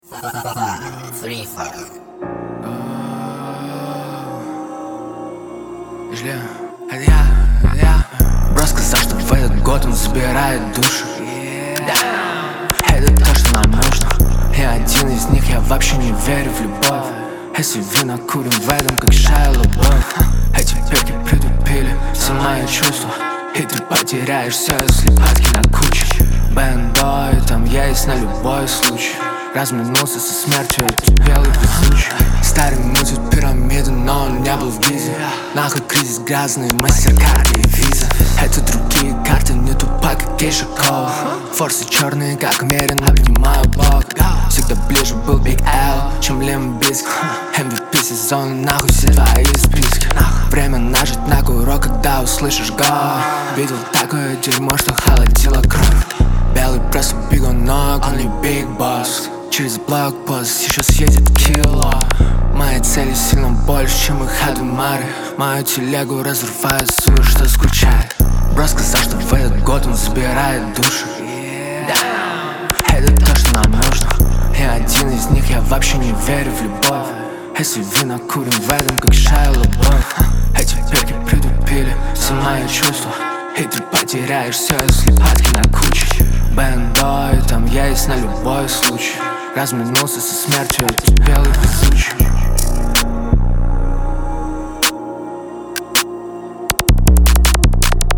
Рэп и Хип-Хоп